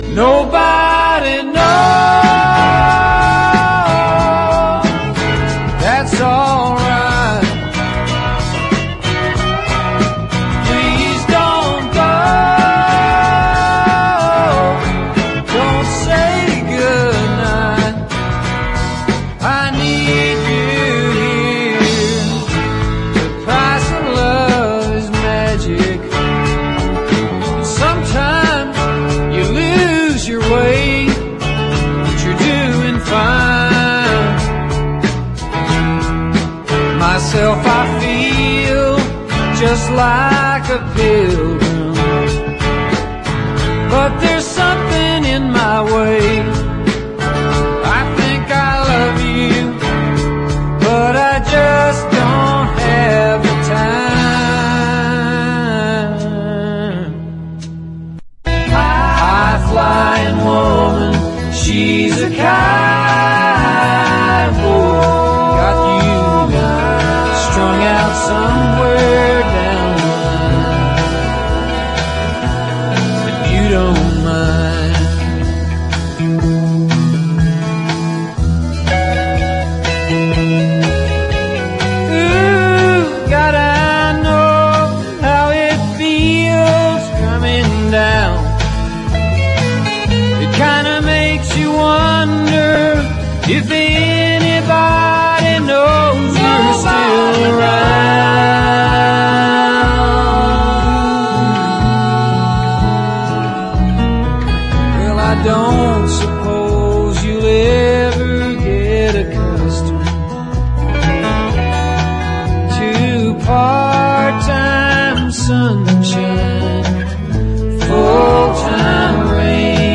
ROCK / 70'S / SWAMP ROCK / COUNTRY ROCK / BLUES ROCK
乾いた空気感と生々しいサウンドが魅力。